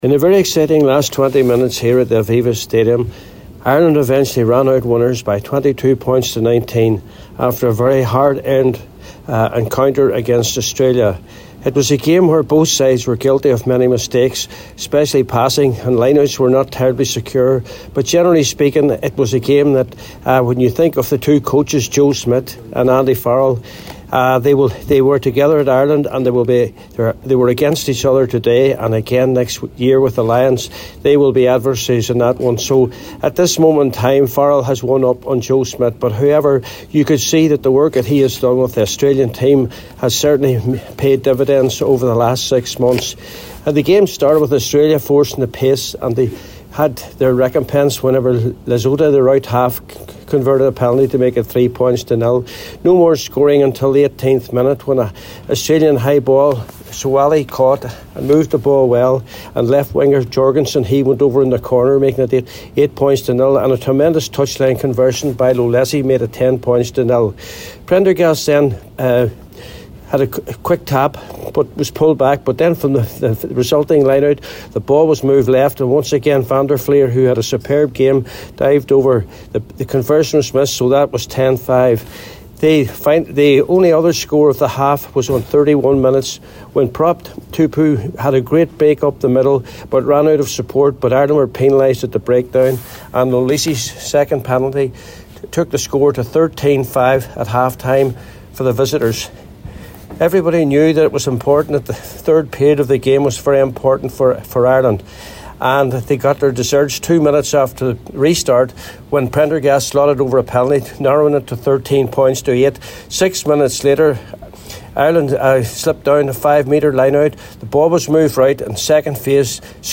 the full-time report